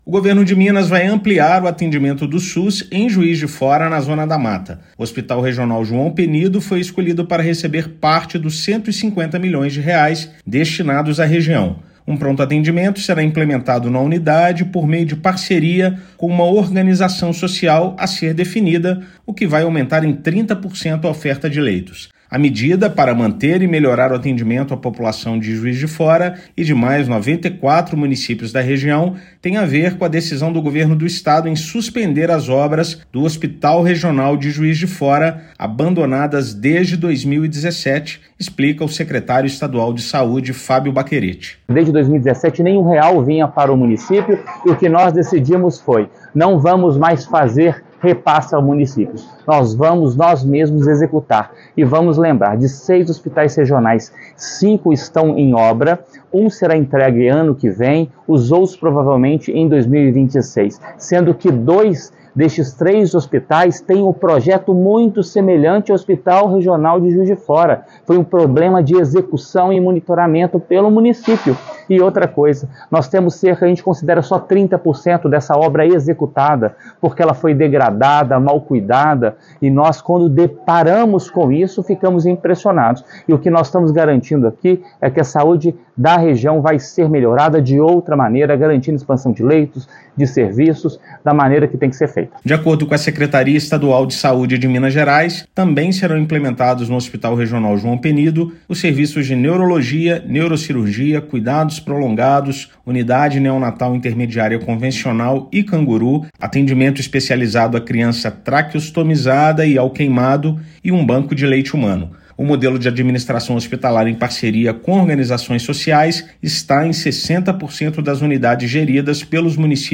Parceria possibilitará diversas melhorias para a população, como abertura da Unidade de Queimados e acompanhamento de crianças traqueostomizadas. Ouça matéria de rádio.